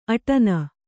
♪ aṭana